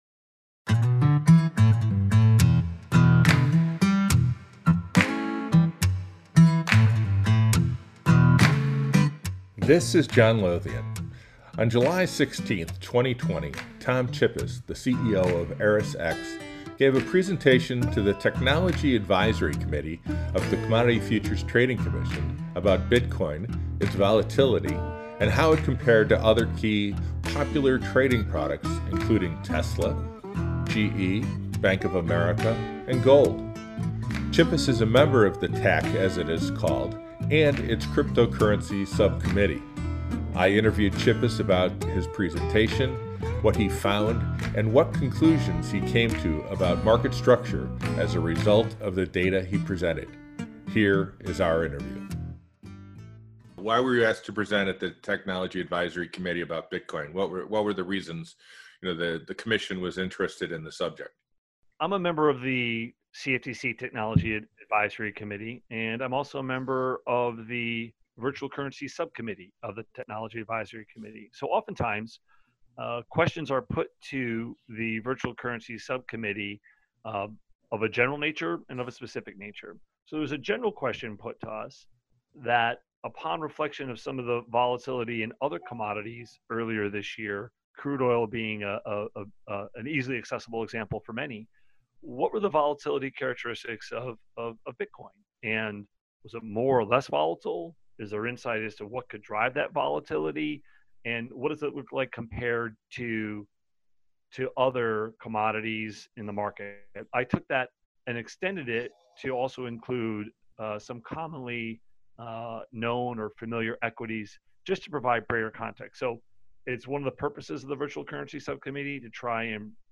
Here is the podcast interview.